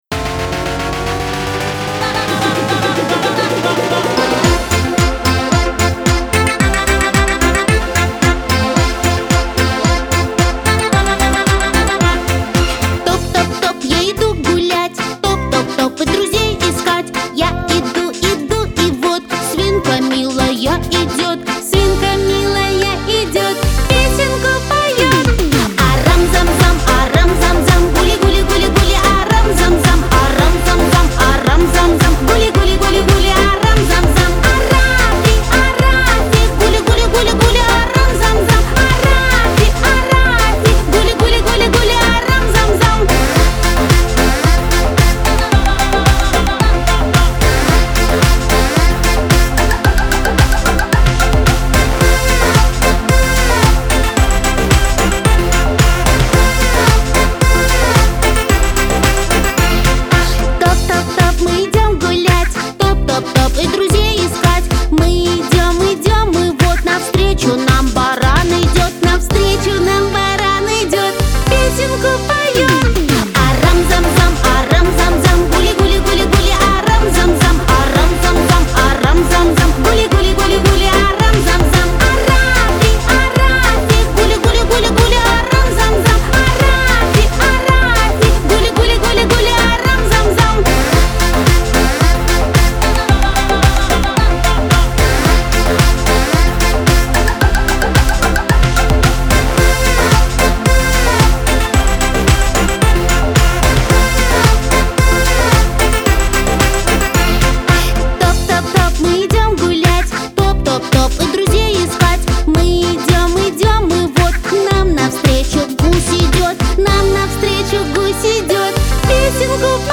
• Качество: Хорошее
• Жанр: Детские песни
танцевальная